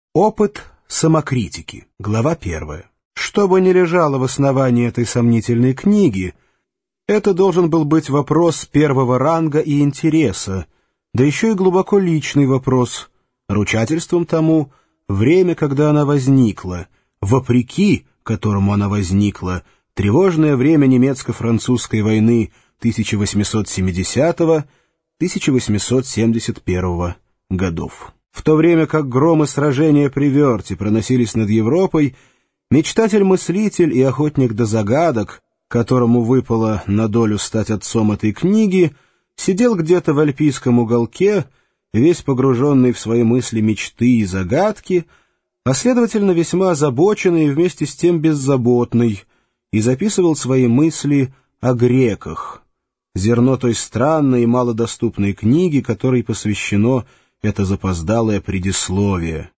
Аудиокнига Рождение трагедии из духа музыки | Библиотека аудиокниг